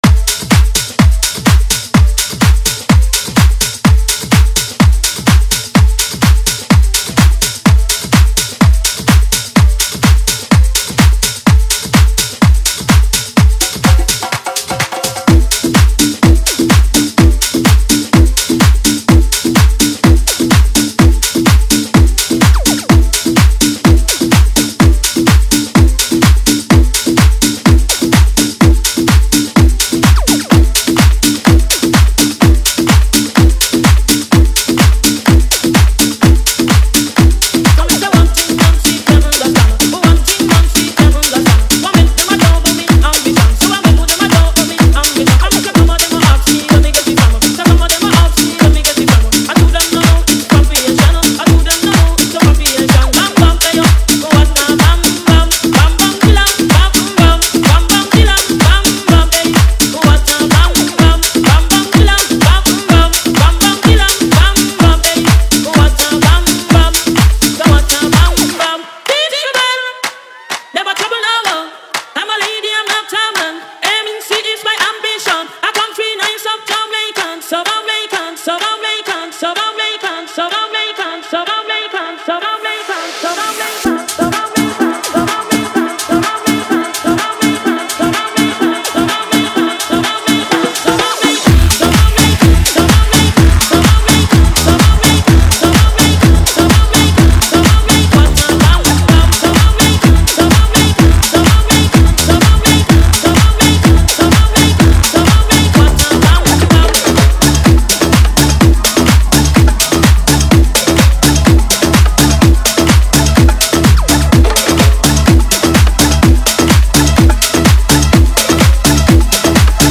• Deep Afro-house bass with strong groove
• Clean and professional mixing
• Genre: Club Mix / Afro House
• High Energy Afro House Beat
It is an Afro-house and club-style DJ remix.